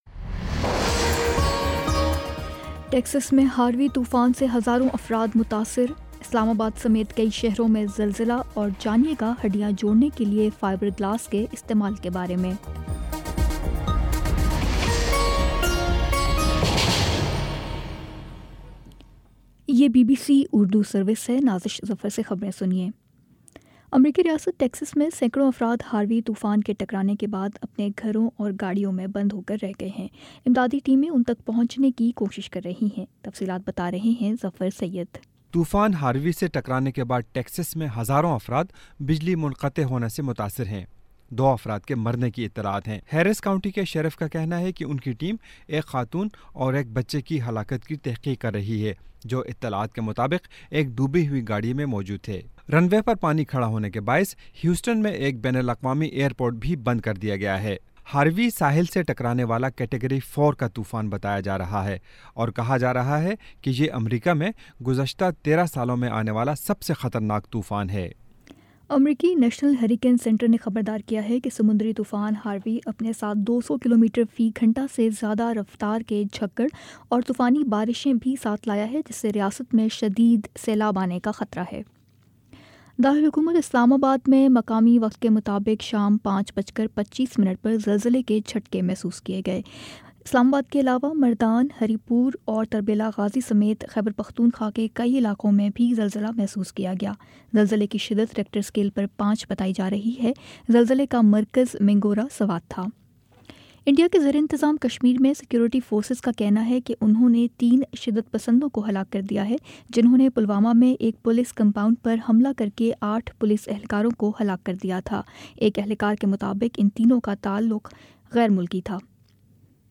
اگست 27 : شام سات بجے کا نیوز بُلیٹن